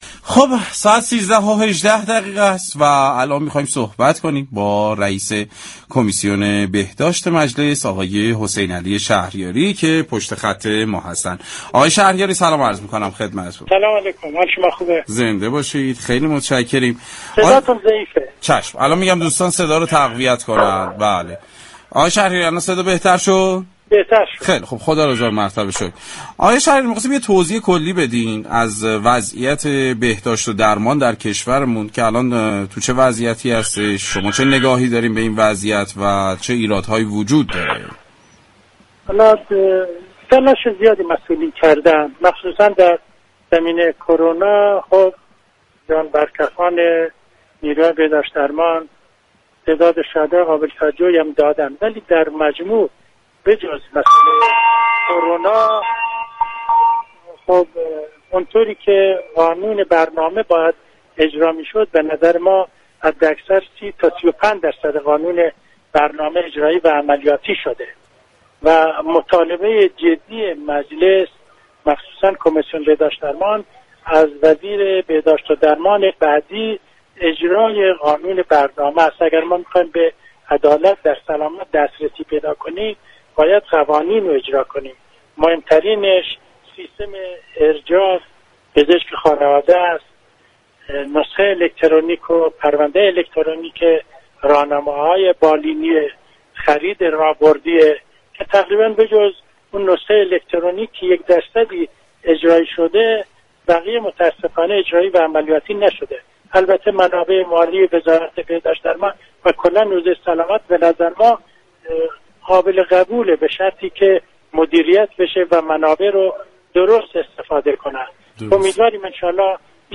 به گزارش پایگاه اطلاع رسانی رادیو تهران، حسینعلی شهریاری رئیس كمیسیون بهداشت و درمان مجلس در گفتگو با برنامه سعادت آباد روز 9 خرداد در ارائه توضیحی كلی به وضعیت فعلی بهداشت و درمان در كشور گفت: مسئولان تلاش زیادی به ویژه در دوره كرونا داشته اند و جان بركفان نیروهای بهداشت و درمان هم شهدای زیادی دادند ولی در مجموع به غیر از كرونا حداكثر 30 تا 35 درصد قانون برنامه اجرایی و عملیاتی شده است.